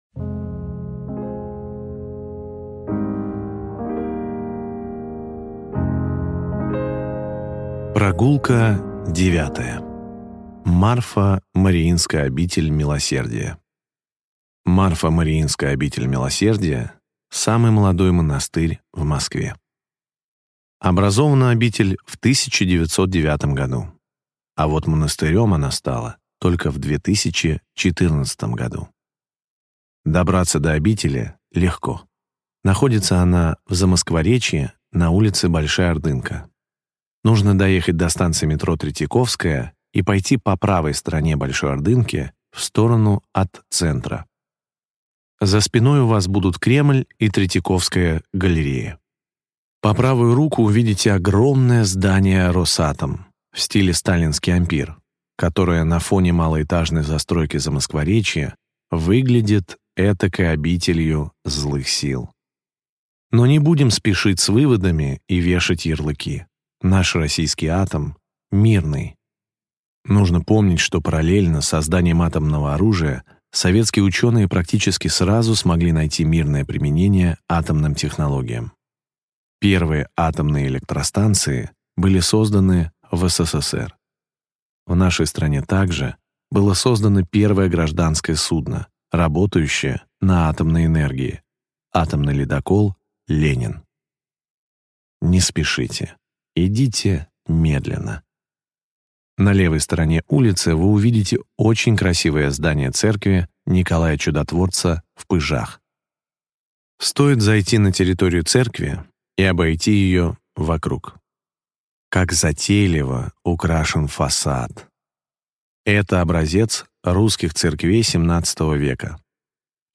Аудиокнига Монастырский пояс Москвы. Глава 9. Марфо-Мариинская обитель милосердия | Библиотека аудиокниг